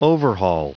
Prononciation du mot overhaul en anglais (fichier audio)
Prononciation du mot : overhaul